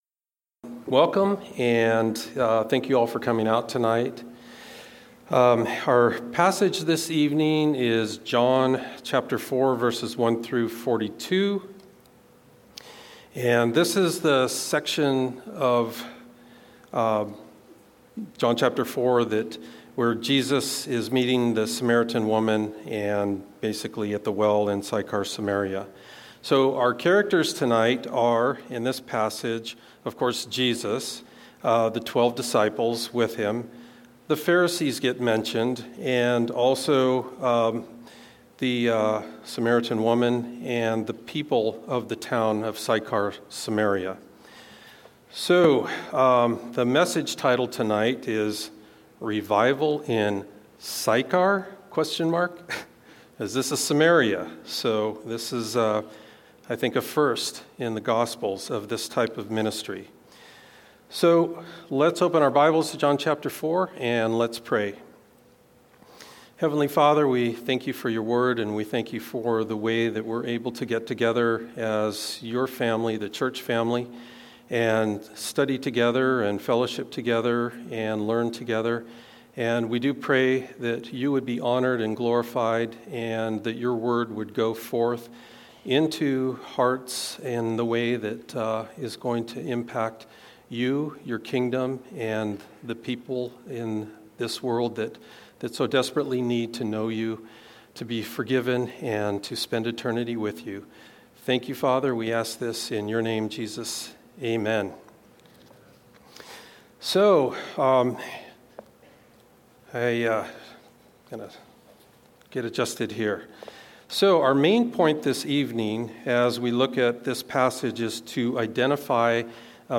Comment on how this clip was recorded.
A message from the service "Wednesday Evening."